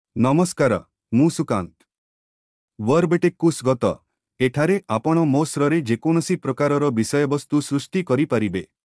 Sukant — Male Odia (India) AI Voice | TTS, Voice Cloning & Video | Verbatik AI
Sukant — Male Odia AI voice
Sukant is a male AI voice for Odia (India).
Voice sample
Listen to Sukant's male Odia voice.
Male